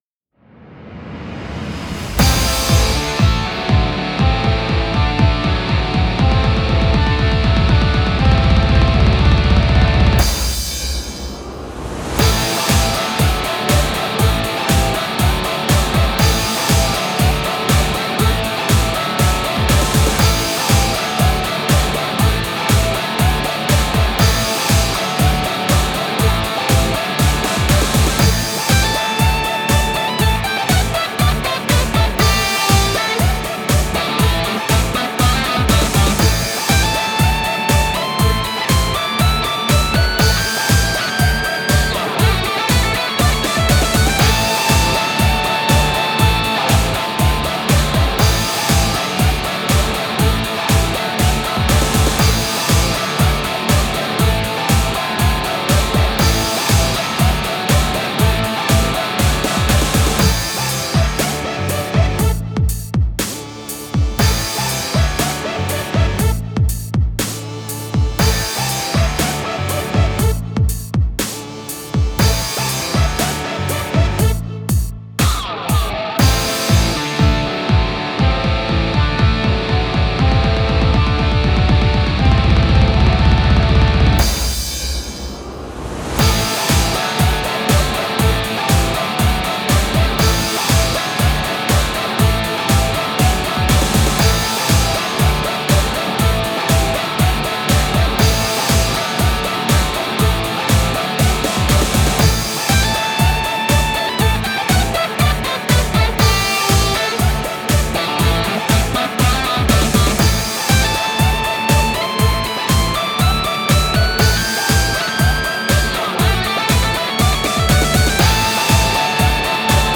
かっこいい、疾走感、バトル、戦闘、ギター、ロック】イメージのオリジナルフリーBGMです、